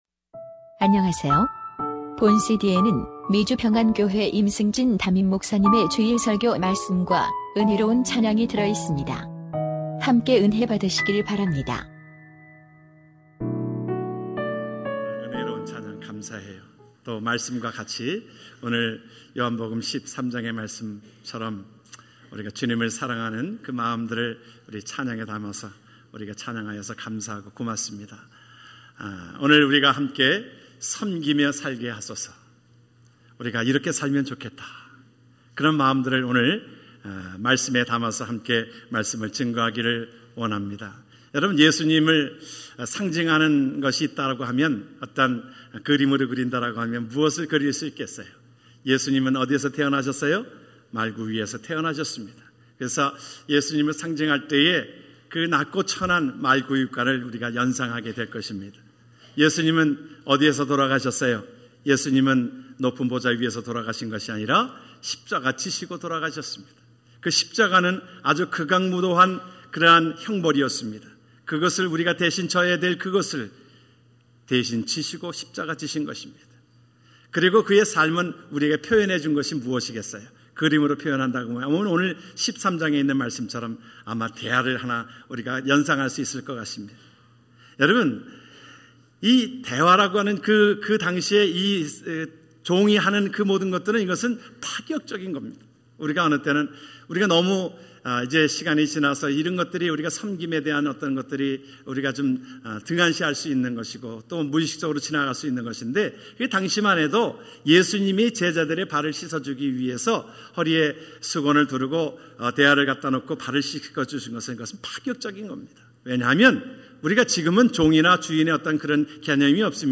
2015년 8월9일 주일설교 섬기며 살게하소서 요13장12절-17절 오늘 말씀은 예수님께서 십자가를 지시기 전 바로 앞의 상황입니다.